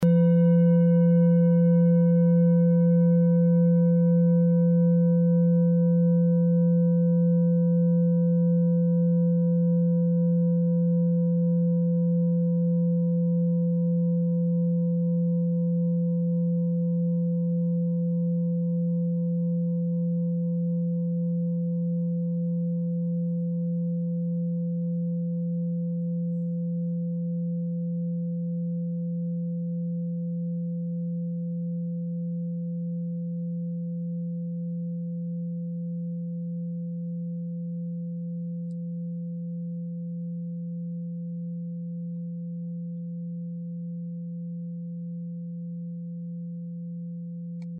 Klangschale Bengalen Nr.4
Klangschale-Gewicht: 970g
Klangschale-Durchmesser: 19,2cm
Die Klangschale kommt aus einer Schmiede in Bengalen (Ostindien). Sie ist neu und wurde gezielt nach altem 7-Metalle-Rezept in Handarbeit gezogen und gehämmert.
klangschale-ladakh-4.mp3